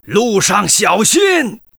文件 文件历史 文件用途 全域文件用途 Rt_fw_03.ogg （Ogg Vorbis声音文件，长度1.8秒，115 kbps，文件大小：25 KB） 源地址:游戏语音 文件历史 点击某个日期/时间查看对应时刻的文件。 日期/时间 缩略图 大小 用户 备注 当前 2018年5月20日 (日) 14:52 1.8秒 （25 KB） 地下城与勇士  （ 留言 | 贡献 ） 分类:诺顿·马西莫格 分类:地下城与勇士 源地址:游戏语音 您不可以覆盖此文件。